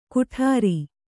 ♪ kuṭhāri